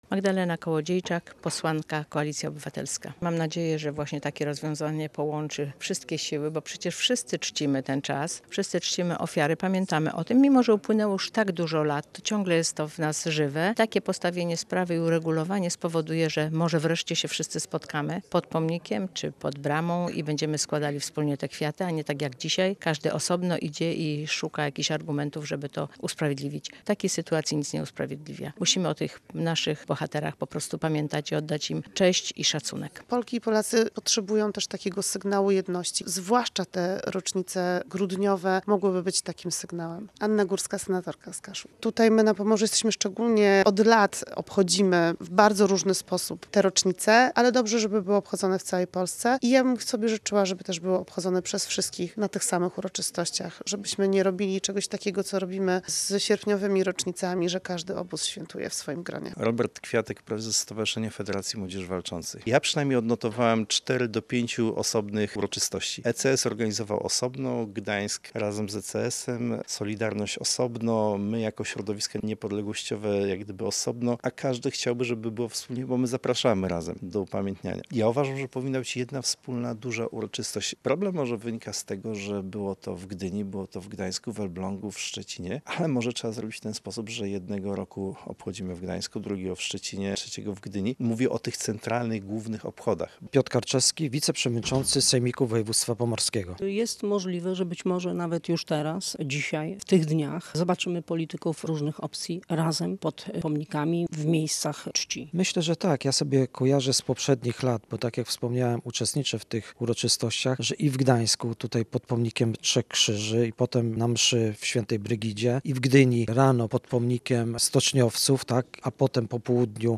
Polska pamięta o tragicznych wydarzeniach na Wybrzeżu. Posłuchaj głosu polityków, historyków i dziennikarzy